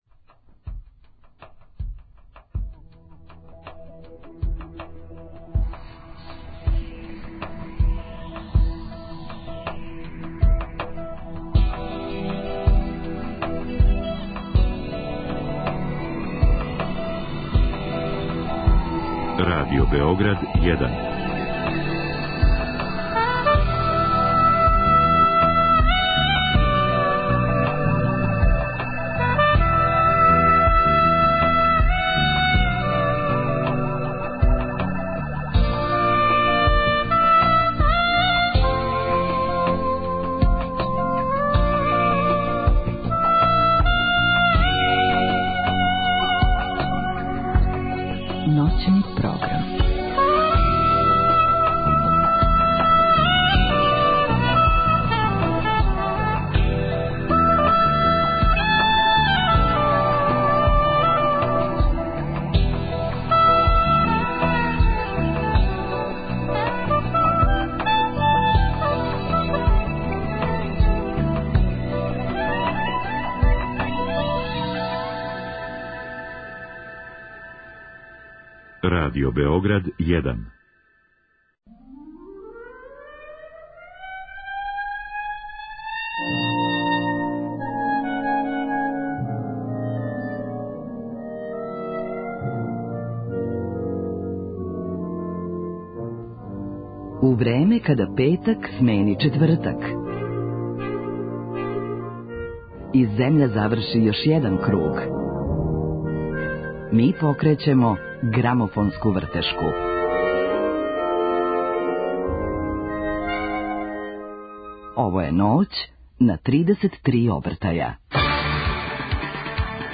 Гости Ноћи на 33 обртаја биће ВИС Лимунада. Са члановима вокално инструменталног састава разговараћемо о грамофону који су купили, о времеплову којим публику враћају у средину прошлог века.